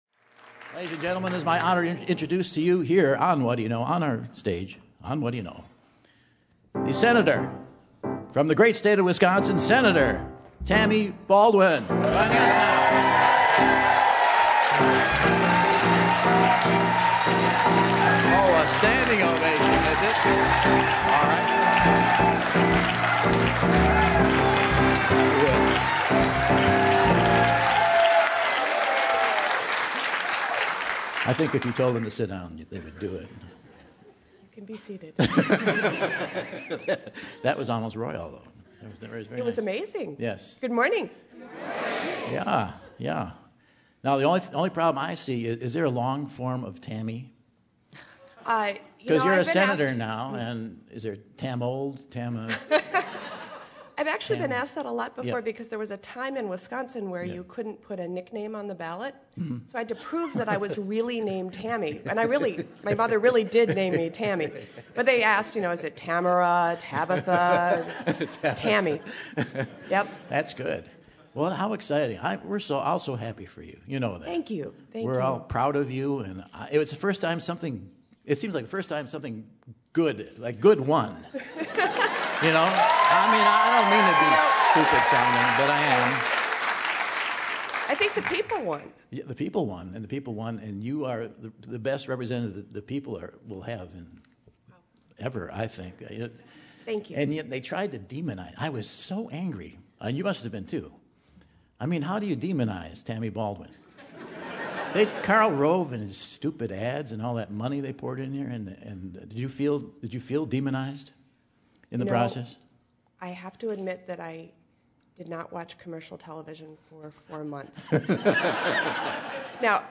US Senator-elect from Wisconsin, Tammy Baldwin, returns to the Monona Terrace stage for her first national appearance since being elected!